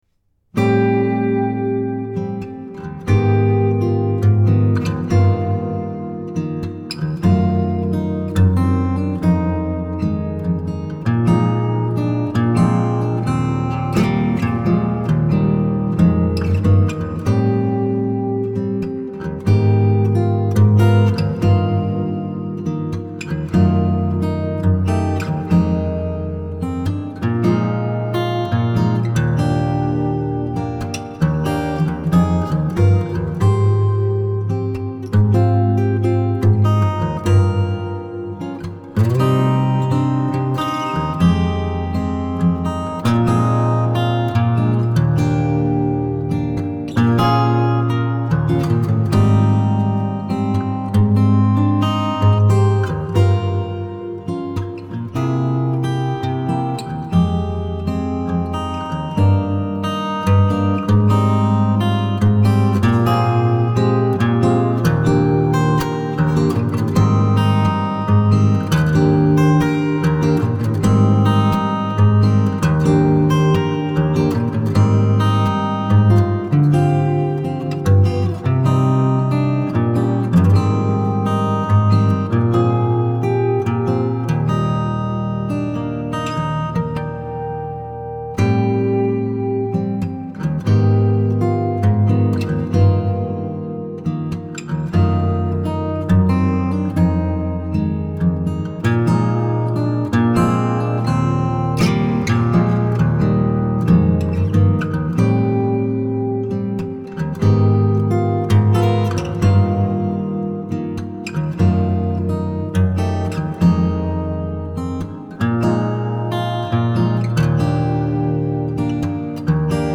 موسیقی کنار تو
آرامش بخش , عصر جدید , گیتار , موسیقی بی کلام